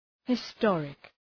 Προφορά
{hı’stɔ:rık}